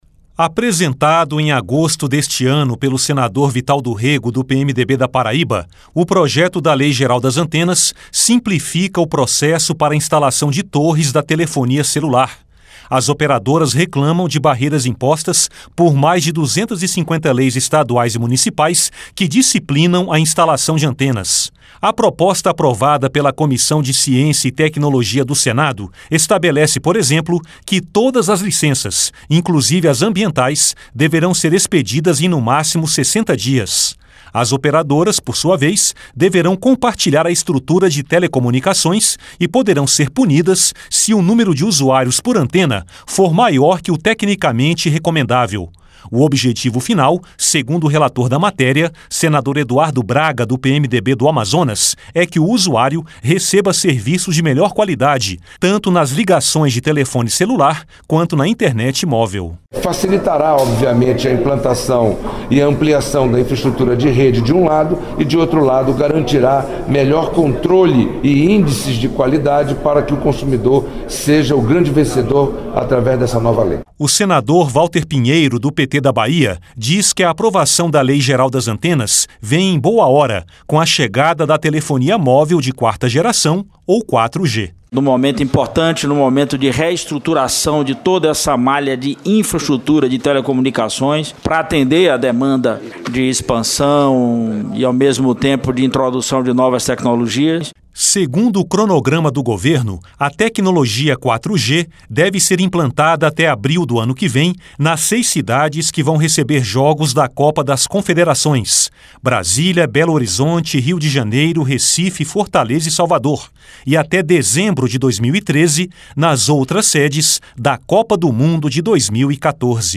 Senador Eduardo Braga
Senador Walter Pinheiro